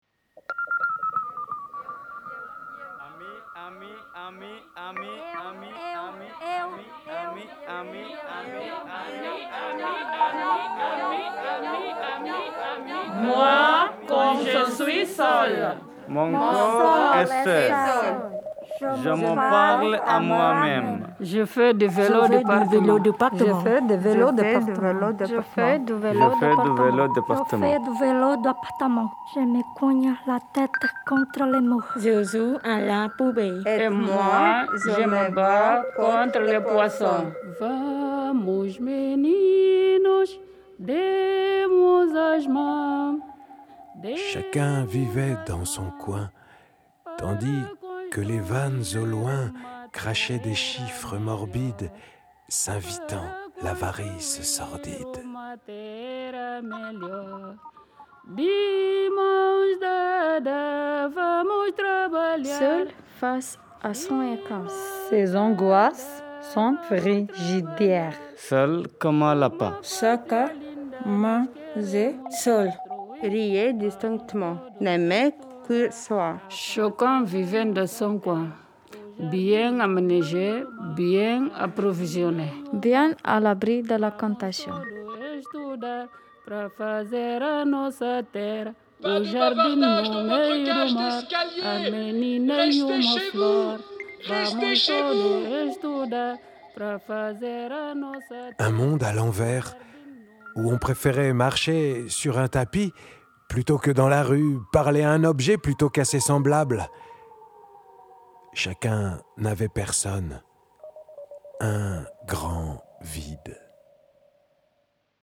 mise en voix du texte, ATELIER DE FRANÇAIS POUR ADULTES PRIMO-ARRIVANTS, MAISON DE QUARTIER DES TEMPS DURABLES, LIMEIL-BRÉVANNES